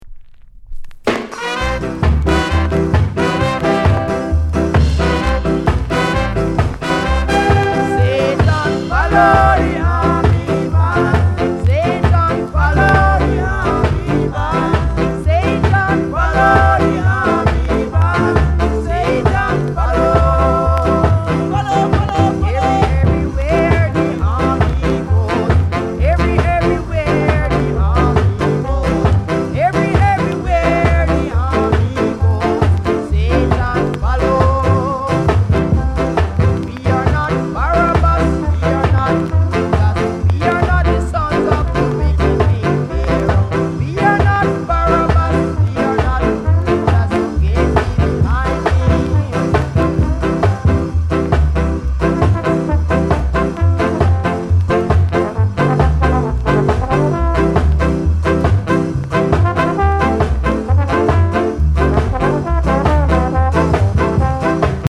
SKA